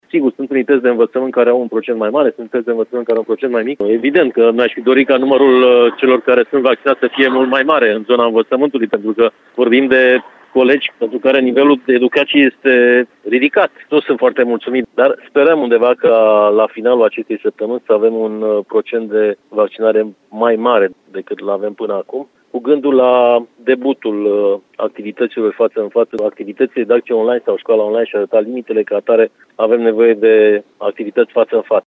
Șeful Inspectoratului Școlar Județean, Marin Popescu, spune că este nemulțumit de această situație și speră ca procentul să crească până la sfârșitul vacanței.